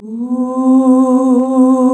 UUUUH   C.wav